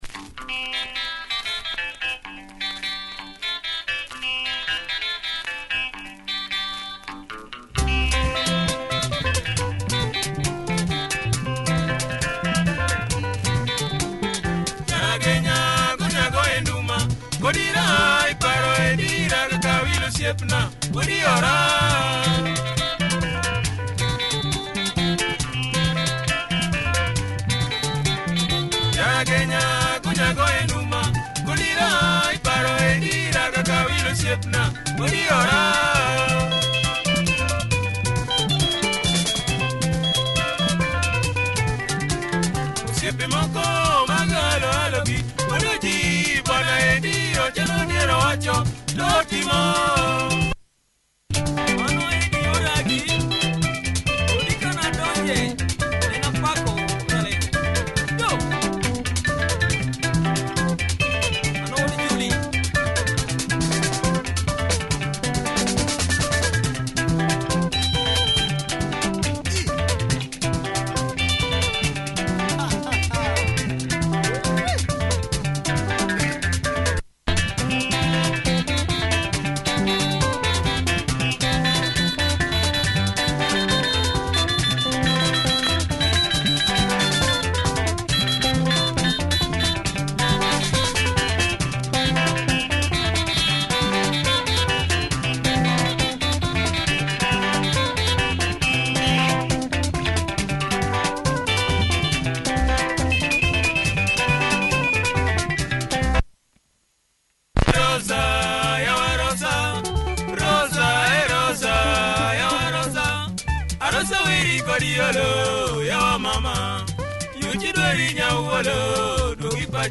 Good tempo and production